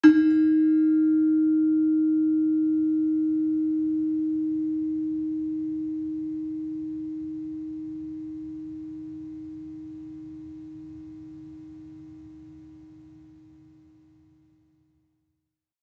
HSS-Gamelan-1
Gender-3-D#3-f.wav